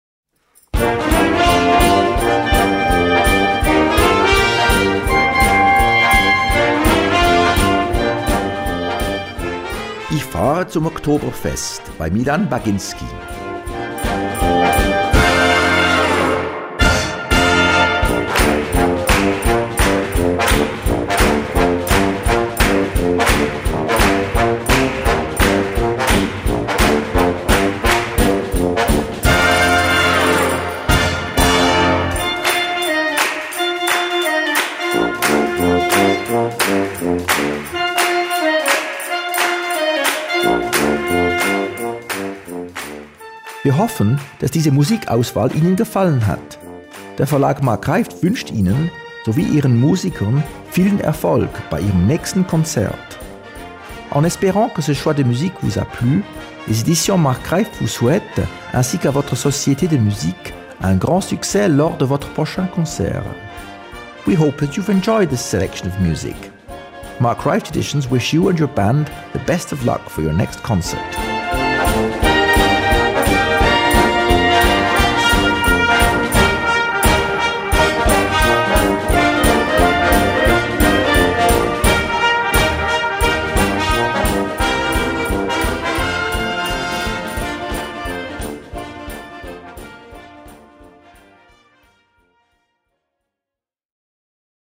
Gattung: Konzertante Unterhalungsmusik
Besetzung: Blasorchester